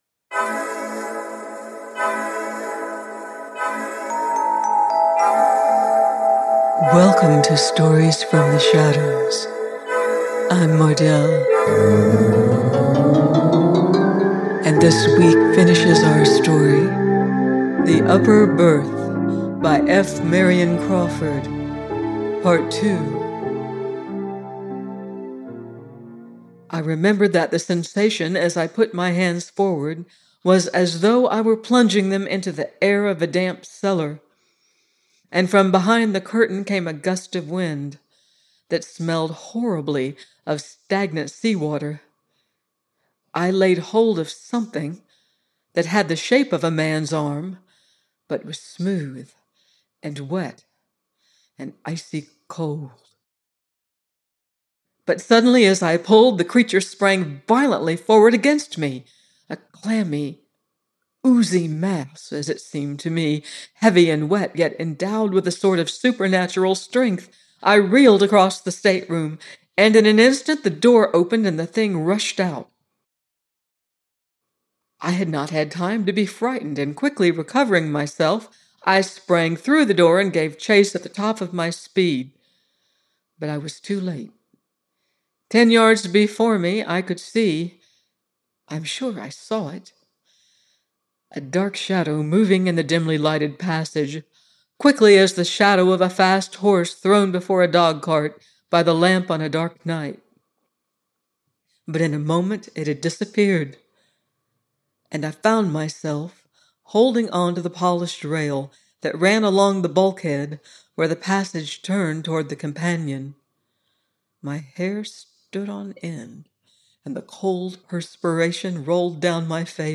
The Upper Berth – part 2 : F. Marion Crawford - audiobook